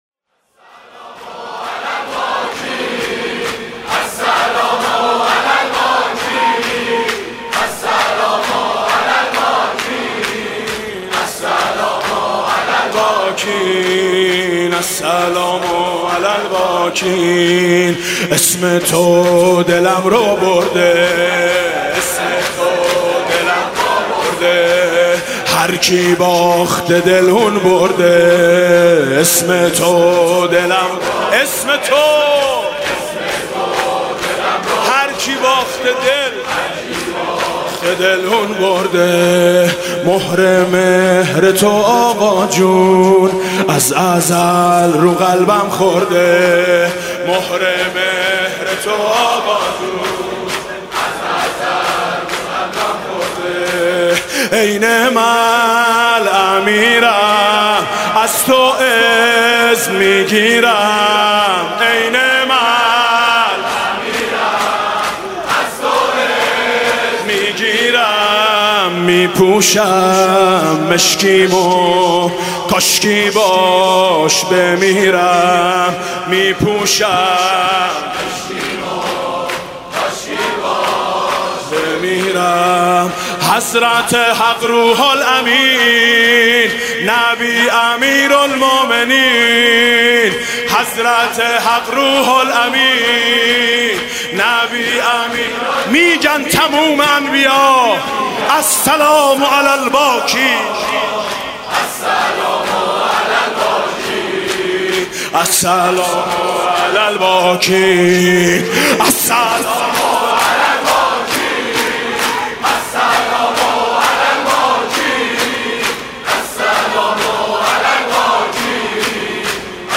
شب ششم محرم 96 - هیئت میثاق - شور - انگار می‌خونه مادری، السلام علی الباکین
محرم 96